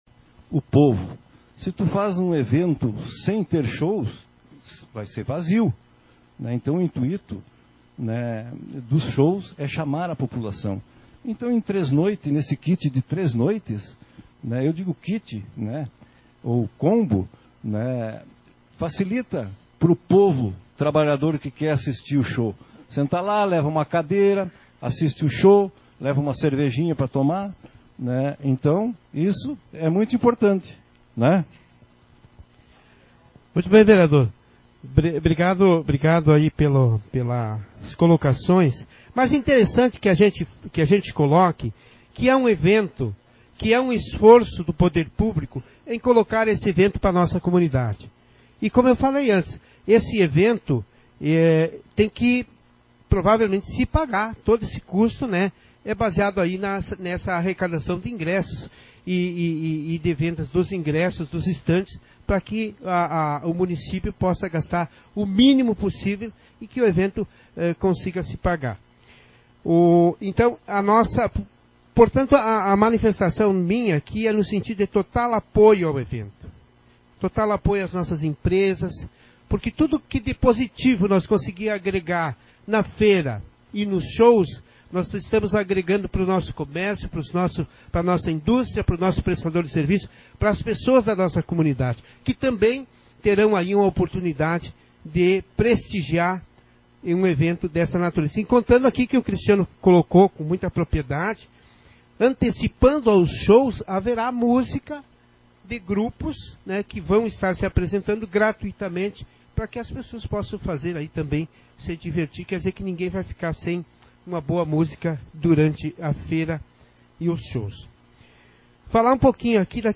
Sessão Ordinária 26/2023